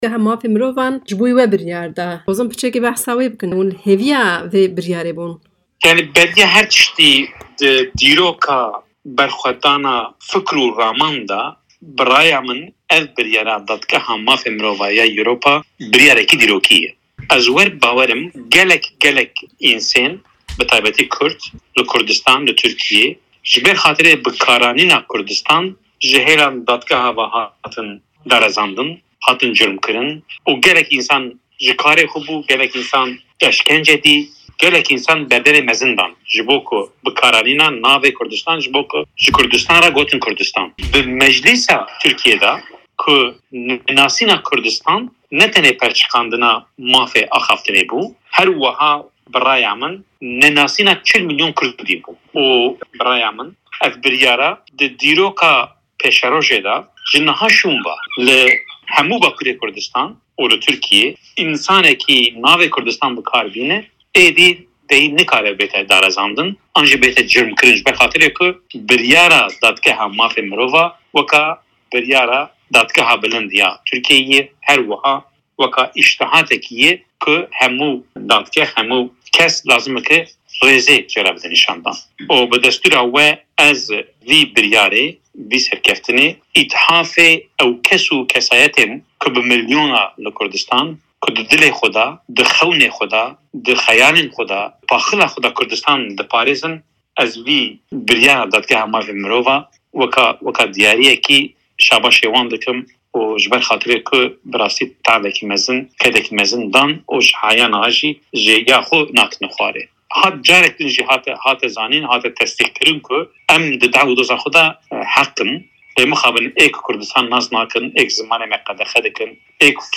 Parlamenterê berê yê bajarê Ruhayê Osman Baydemîr di hevpeyvînekê de ligel Dengê Amerîka helwesta xwe hember biryara Dadgeha Mafên Mirovan ya Ewrupî (ECHR) derbarê doza wî nîşan da.